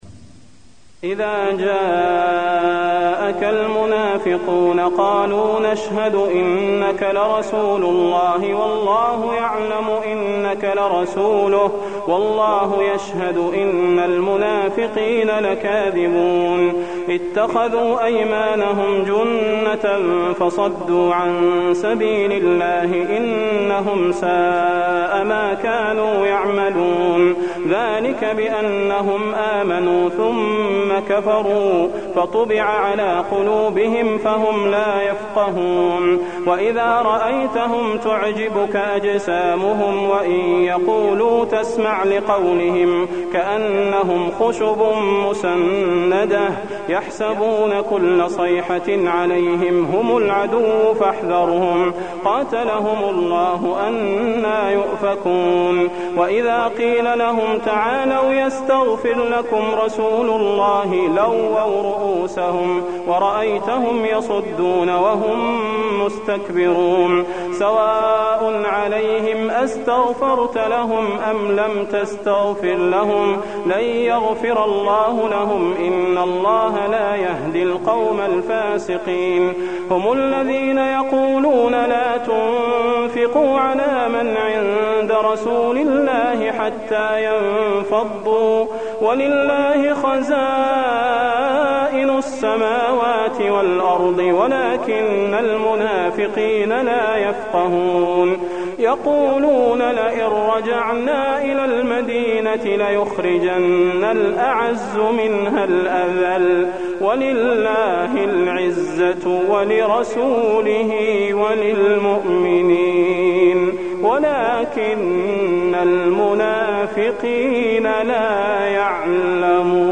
المكان: المسجد النبوي المنافقون